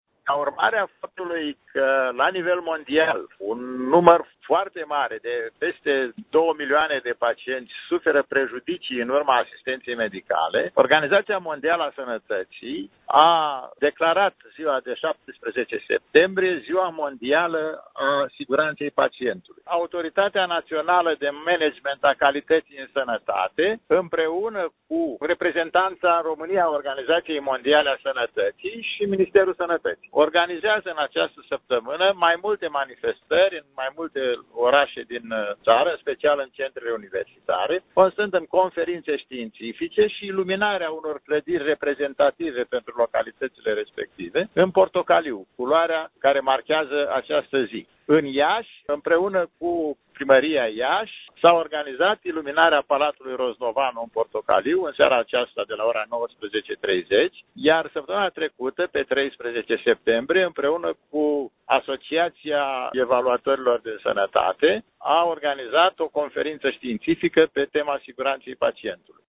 La Iaşi, va fi iluminat astfel Palatul Roznovanu, de la 19,30, a anunţat, la Radio Iaşi, președintele Autorității Naționale de Management al Calității în Sănătate, secretar de stat, Vasile Cepoi: